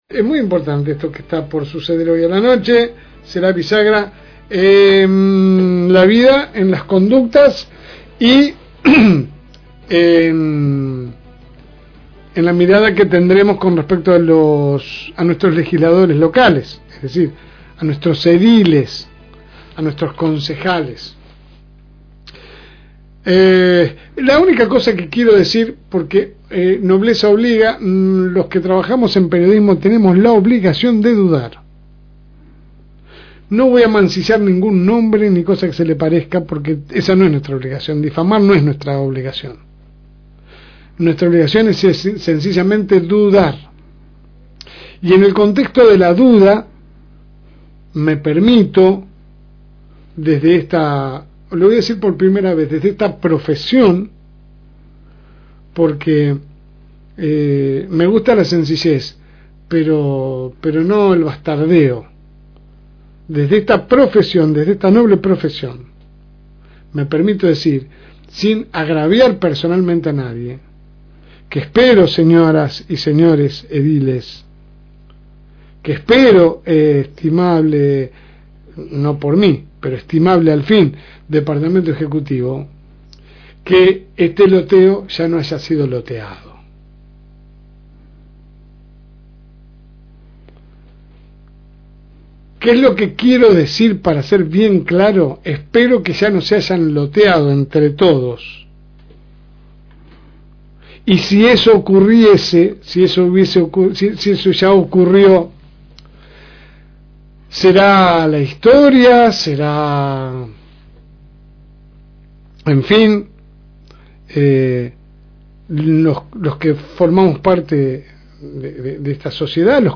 AUDIO – Editorial de La Segunda Mañana